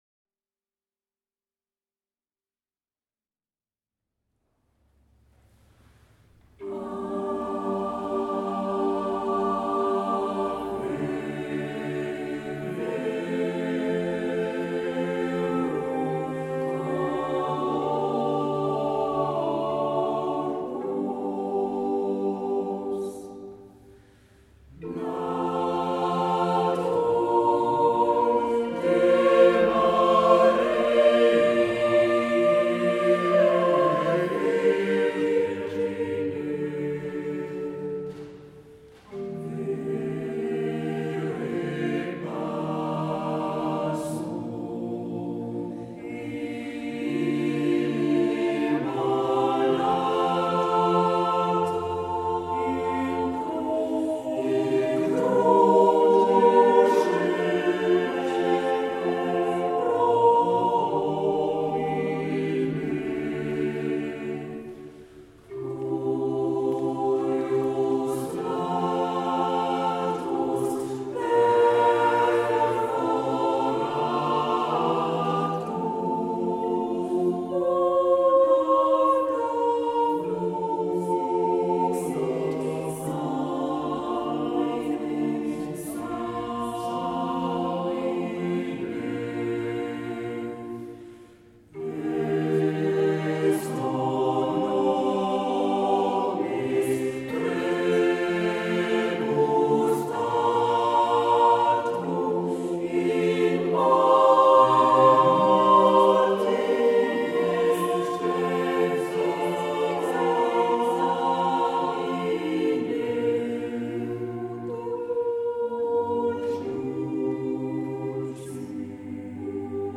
Baroque allemand et anglais pour choeur et orgue - Polyphonie moderne a capella
transposé en la mineur avec l'orgue mésotonique de Cossonay et à 398Hz,